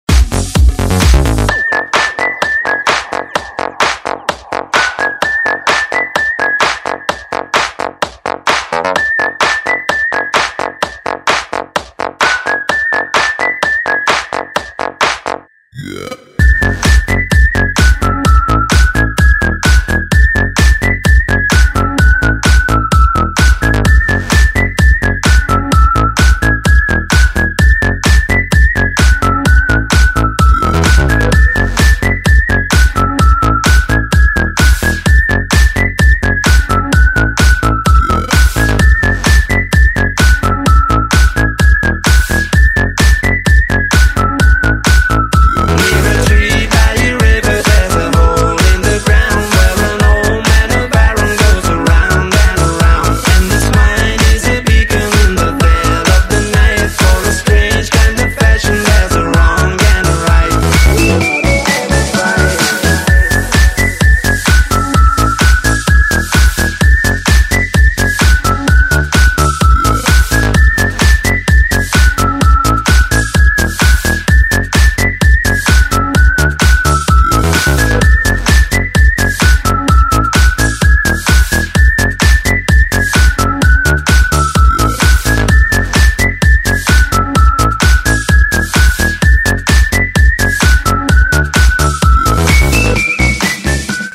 • Качество: 128, Stereo
Трек со свистом, который знает весь мир!!!